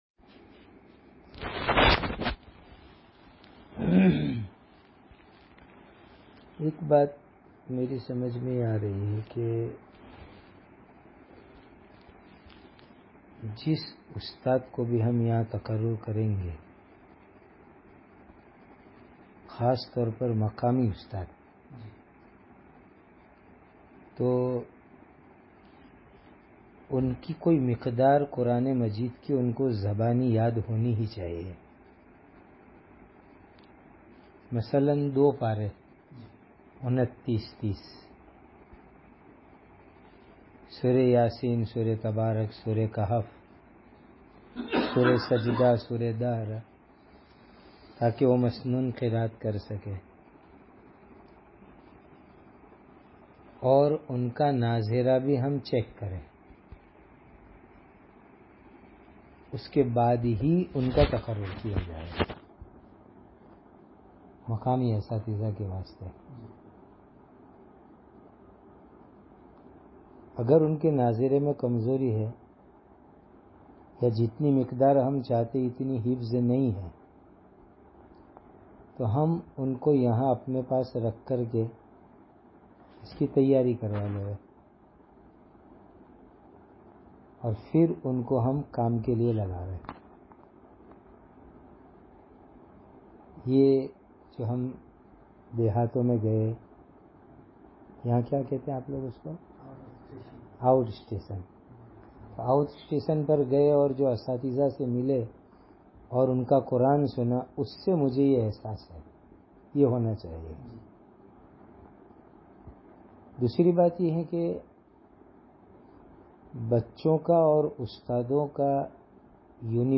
Teachers Me Bayan With English Translation